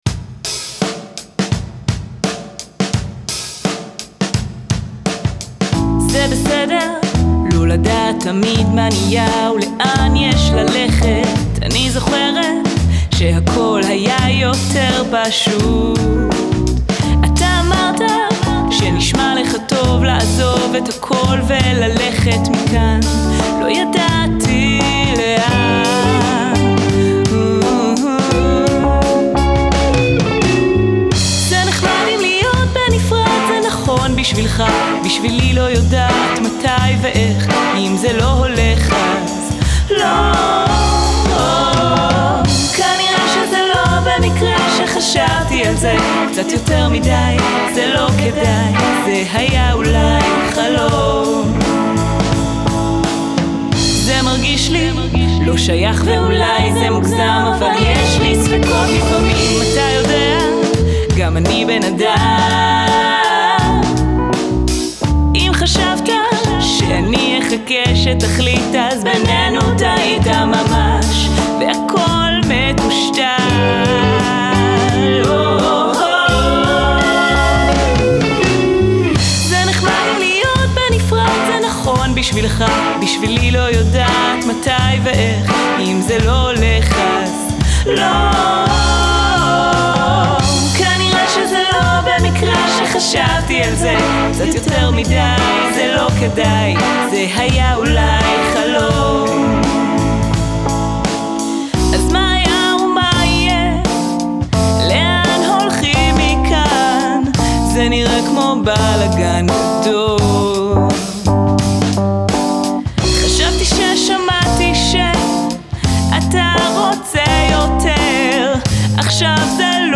מוקלט יפה, חרוזים לא רעים ונאמן לז'אנר.
איכות מעולה, קול מדהים.. כל הכבוד.
הקול שלה ממש טוב, והנגינה מעולה.